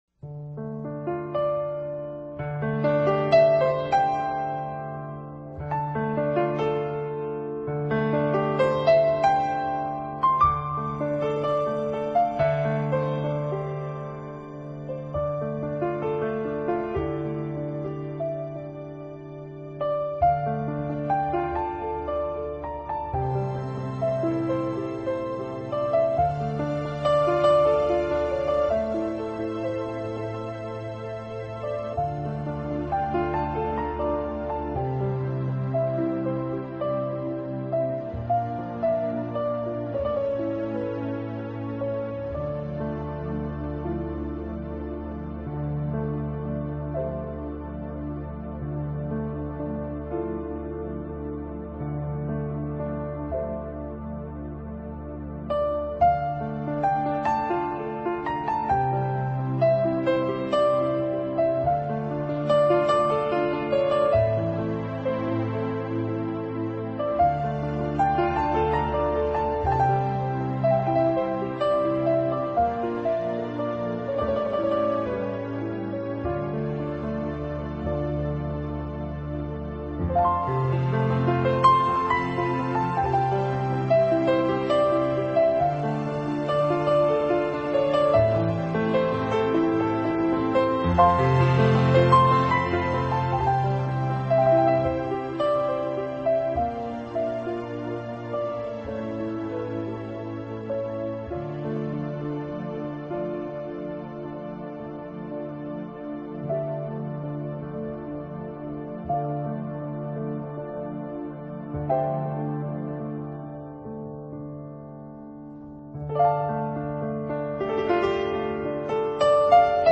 【新世纪】New Age钢琴音乐大师